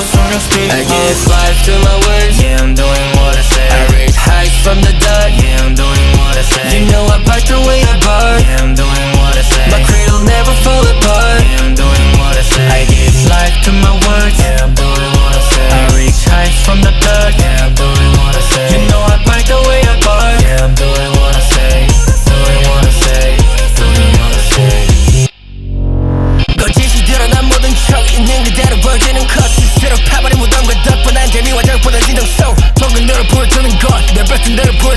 K-Pop Pop
2025-08-22 Жанр: Поп музыка Длительность